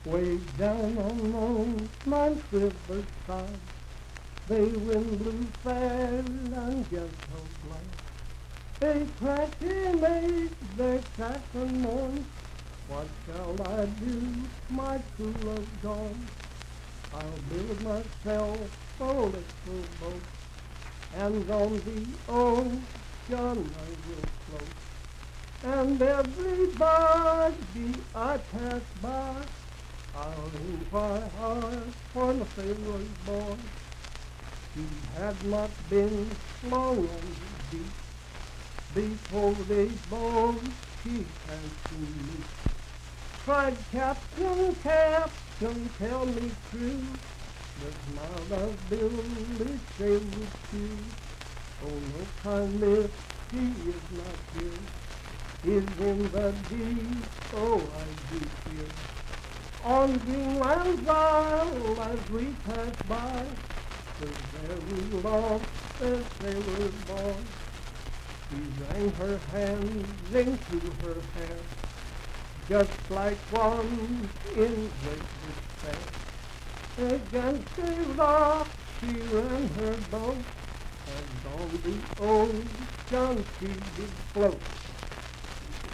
Unaccompanied vocal music
Verse-refrain 5(4).
Voice (sung)